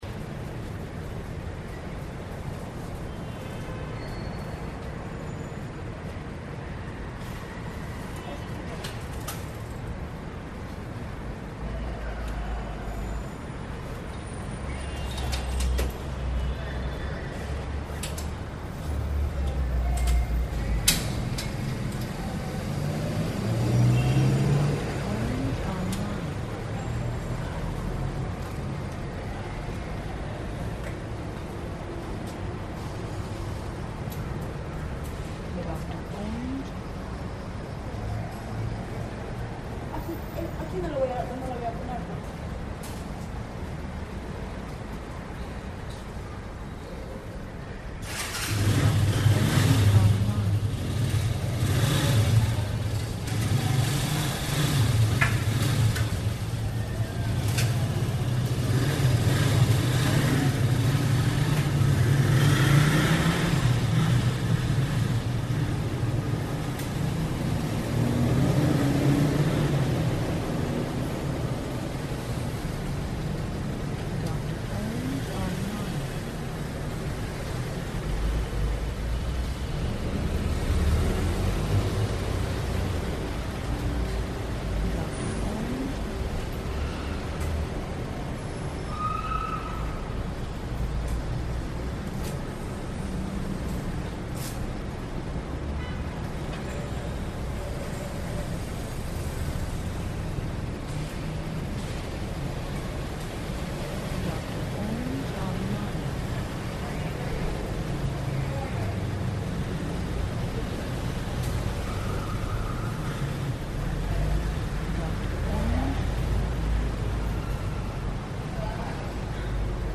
Ambiente Ambisónico Interior Estacionamiento CC
INTERIOR ESTACIONAMIENTO CC, AUTOMOVILES, MUJER HABLA, AMBIENTE MOTOCICLETA ARRANCA Y SE VA, ALARMA Y BOCINA AUTO LEJANA, CONVERSACIONES LEJANAS, RADIO GUARDIA, ALARMA AUTO, CAMIONETA INGRESA.
Archivo de audio AMBISONICO, 96Khz – 24 Bits, WAV.